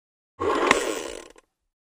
Звук щелчка ручки детонатора